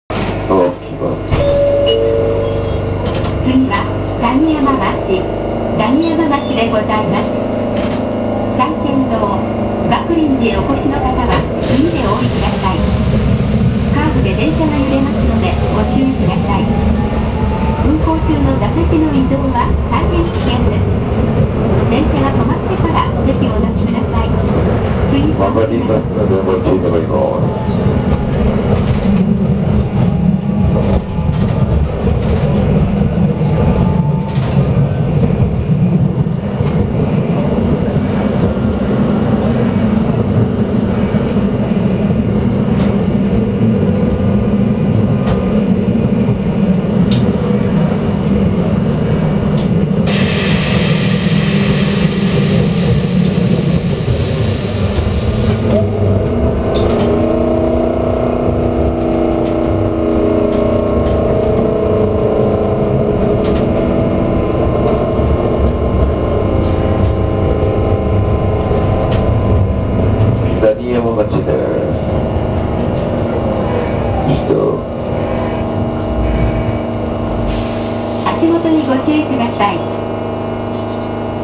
・9200形走行音
【３系統】蔚山町〜段山町（1分21秒：443KB）
8200形以来久々の導入となったVVVF車である9200形。但し、走行音はいまいちよく聞こえません。
あと、CPが五月蠅いです。